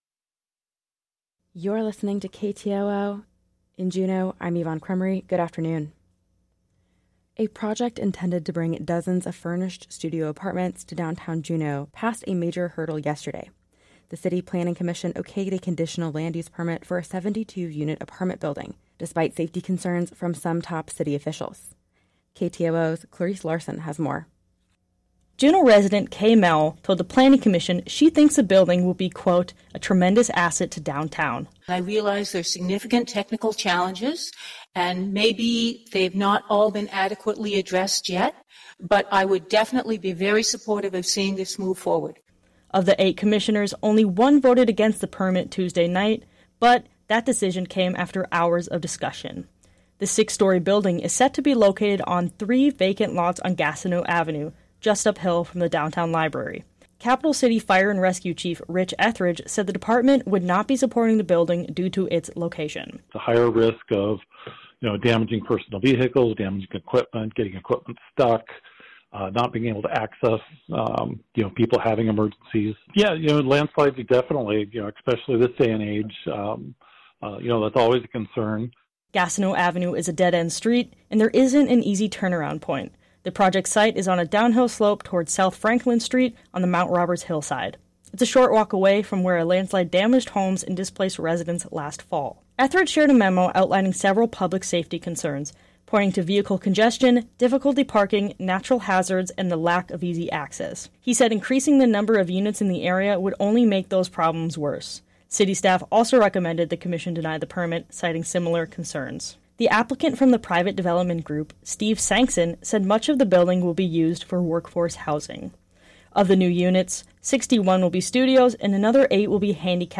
Newscast – Wednesday, Dec. 13, 2023